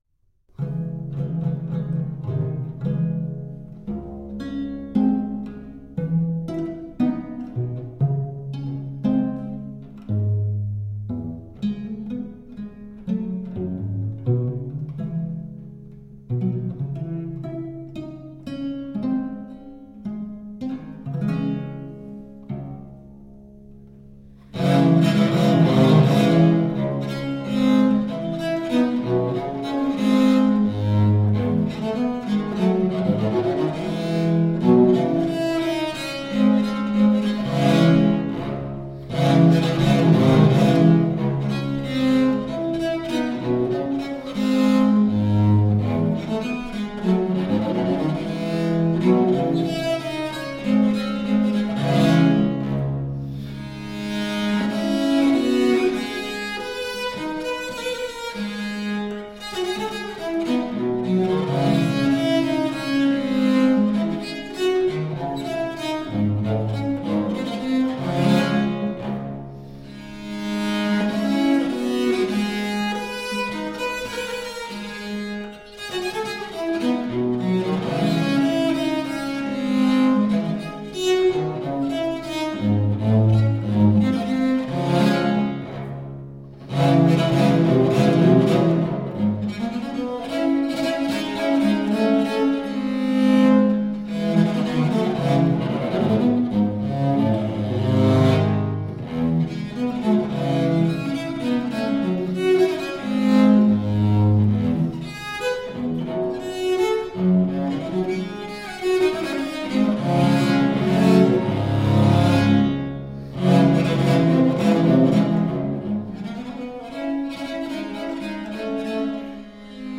Virtuoso viola da gamba.
Classical, Baroque, Renaissance, Instrumental